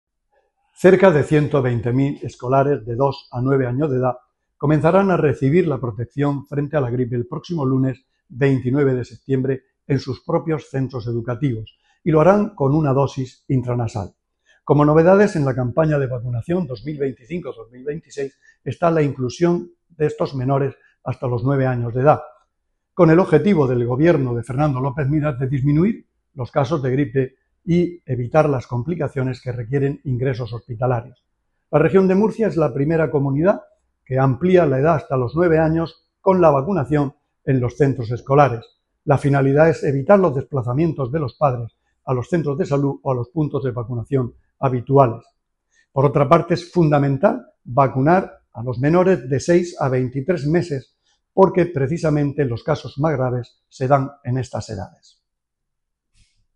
Sonido/ Declaraciones del consejero de Salud, Juan José Pedreño, sobre la campaña de vacunación escolar de gripe de la temporada 2025-2026.